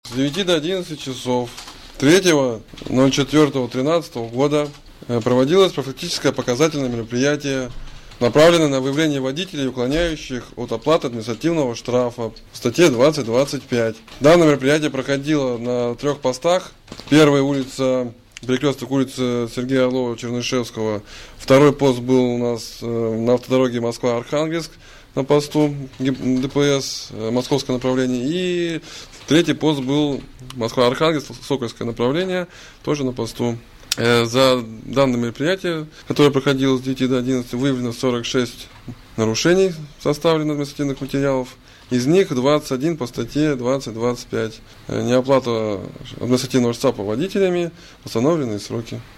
Инспекторы ДПС рассказали о прошедшем рейде